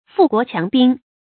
富國強兵 注音： ㄈㄨˋ ㄍㄨㄛˊ ㄑㄧㄤˊ ㄅㄧㄥ 讀音讀法： 意思解釋： 使國家富足，兵力強大。